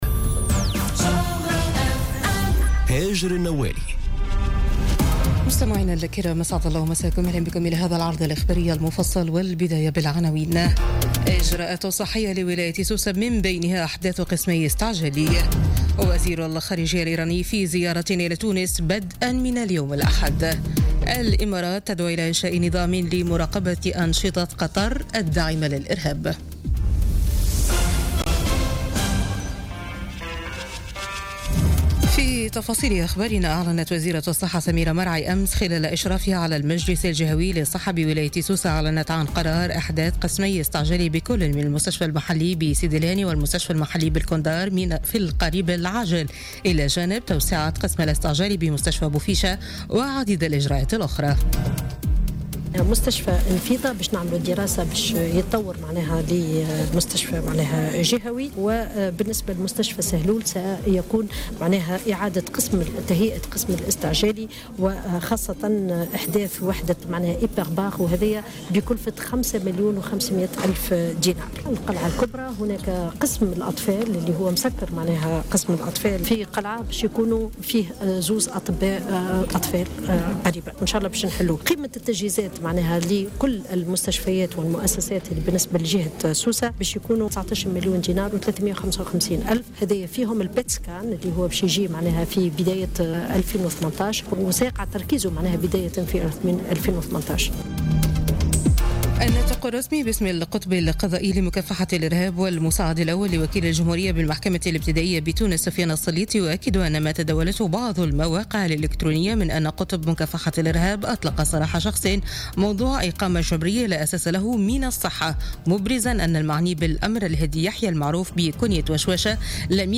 Journal Info 00h00 du dimanche 18 Juin 2017